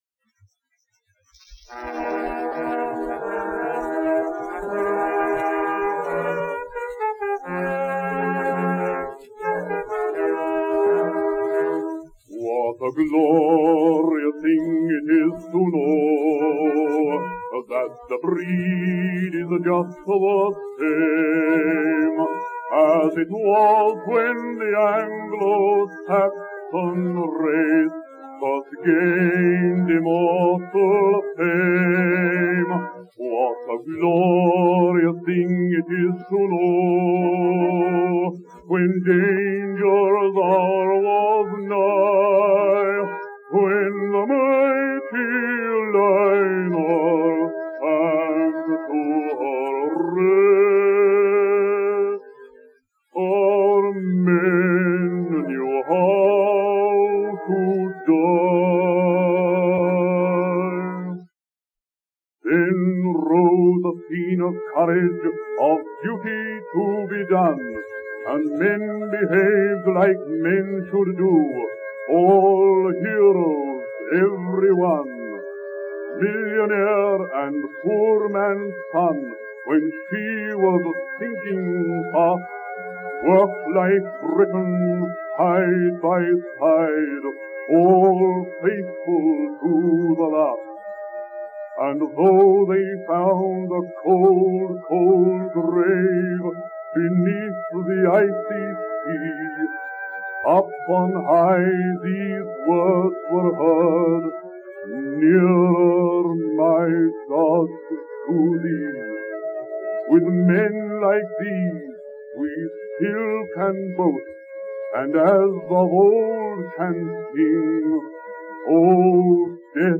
Popular music